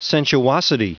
Prononciation du mot sensuosity en anglais (fichier audio)
Prononciation du mot : sensuosity